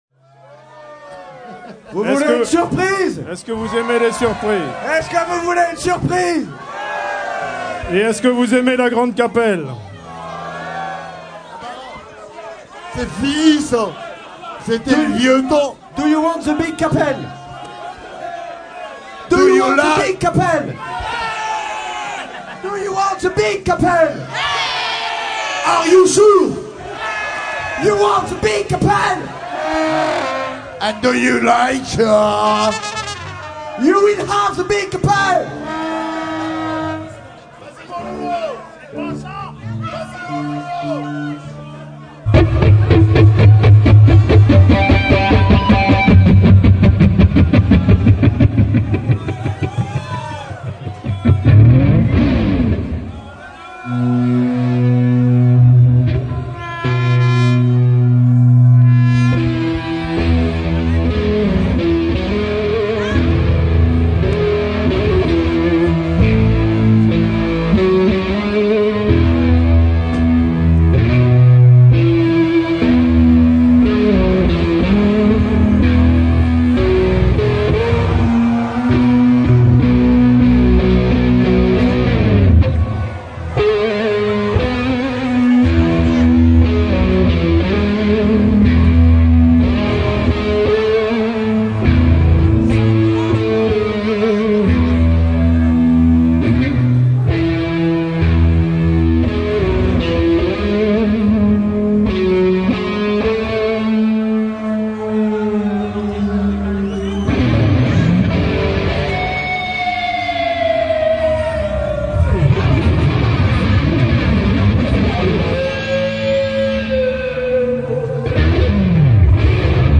Live ! Extraits Rock 'n' Roll !